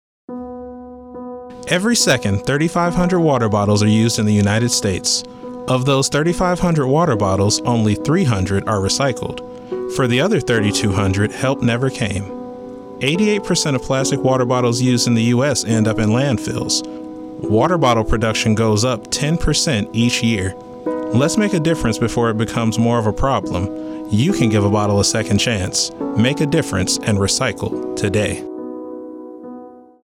NAVAL AIR STATION SIGONELLA, Italy (Nov. 14, 2024) Radio spot highlights the consequences of not properly recycling.